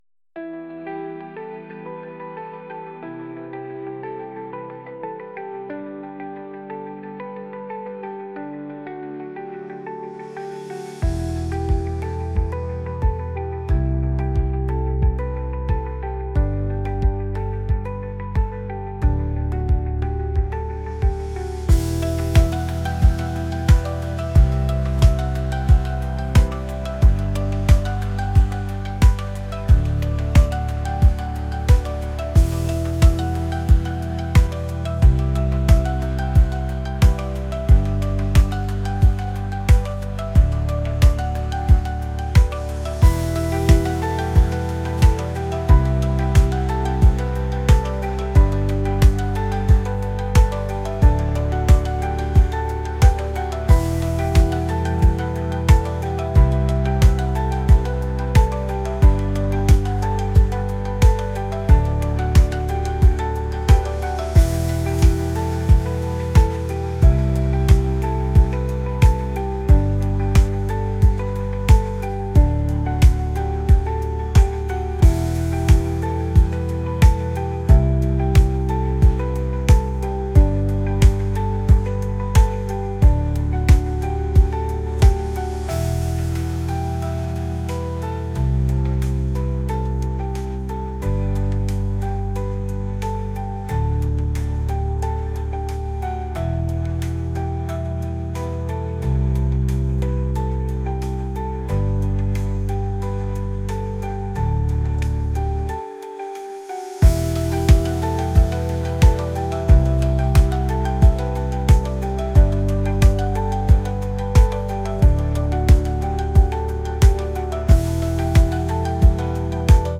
pop | ambient | indie